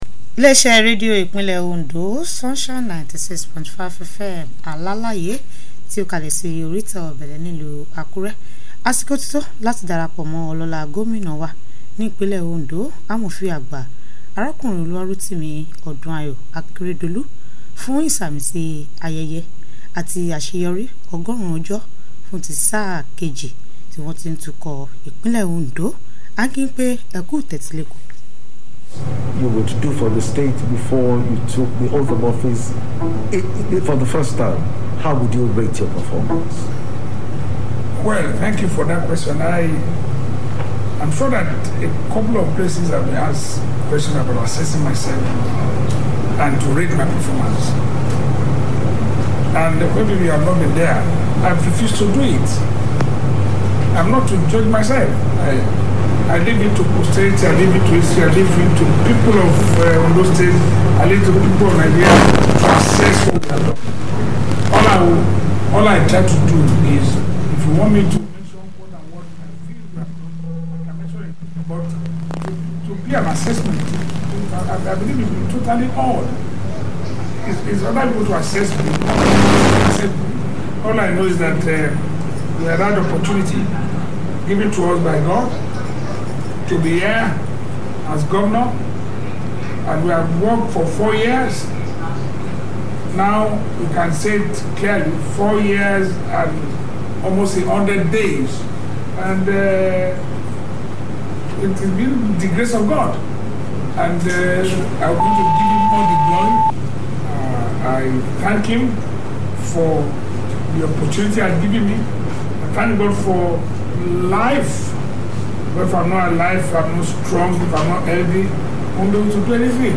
Governor Akeredolu interactive session on the first 100 days of his second tenure
GOVERNOR-SPEECH-EDITED.mp3